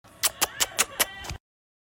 Kisses Shots Sound Button - Free Download & Play